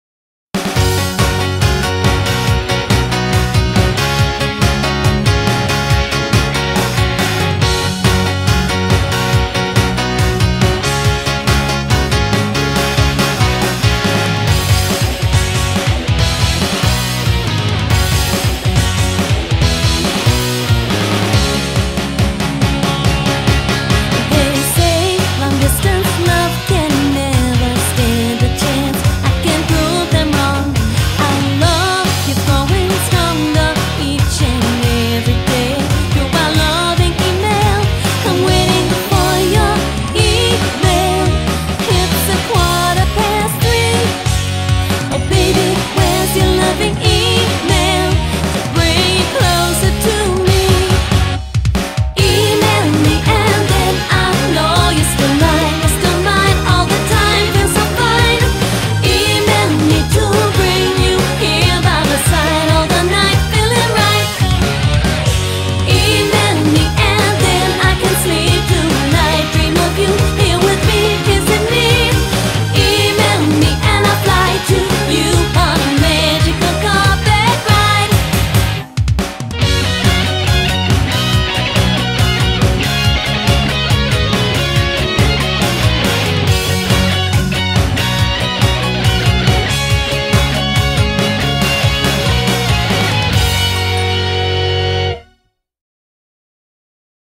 Audio QualityPerfect (High Quality)
140 BPM